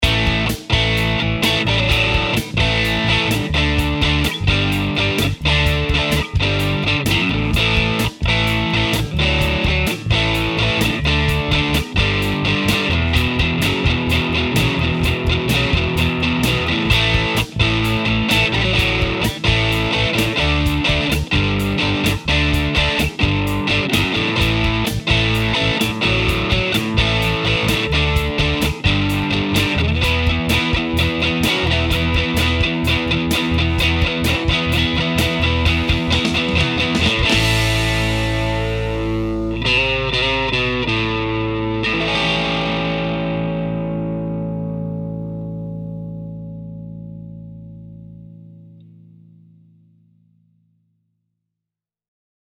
But first, as opposed to doing this last, here’s a short clip featuring the VRX18 to show you how kick-ass it sounds:
For this clip, I used a Strat with just the middle pickup, plugged directly into the VRX18. I just dig that EL84 grind! I had the Channel 2 volume dimed, and the master volume at halfway in full-power mode. It was very loud, much to the chagrin of my wife. 🙂 But I needed to capture at least some of that EL84 compression.